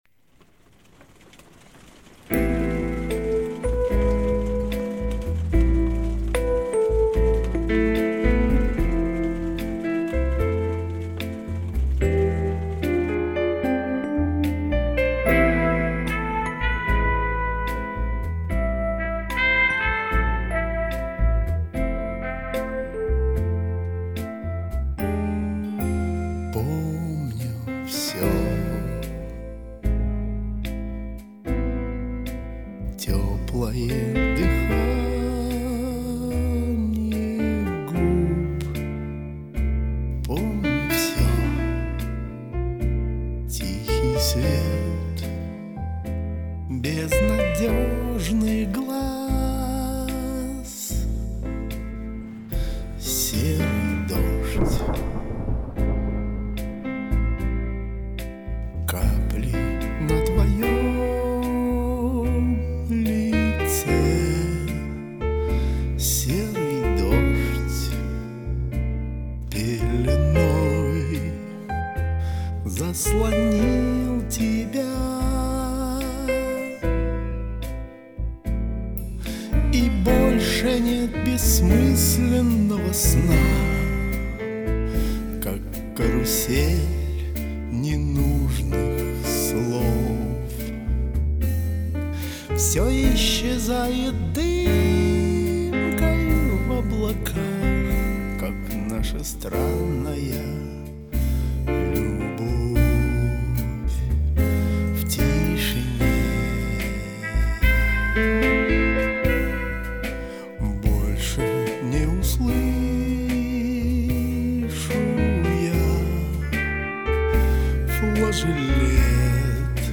с этаким "песком" в голосе